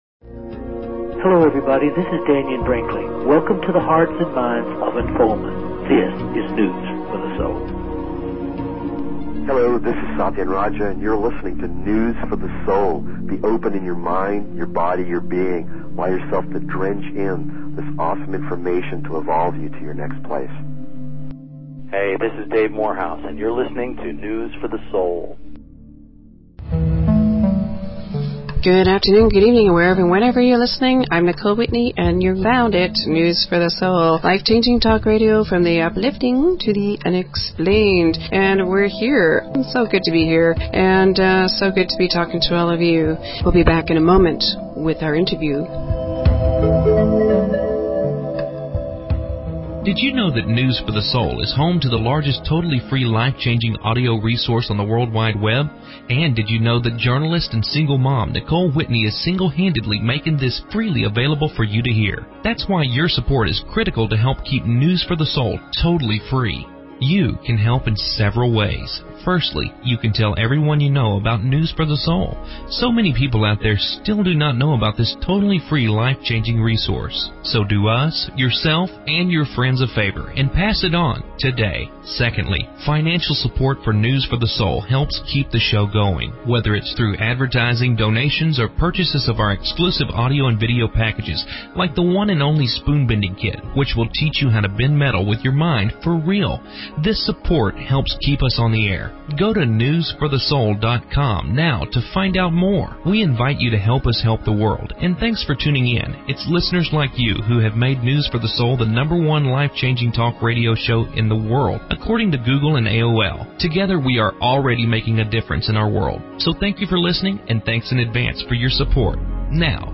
Talk Show Episode, Audio Podcast, News_for_the_Soul and Courtesy of BBS Radio on , show guests , about , categorized as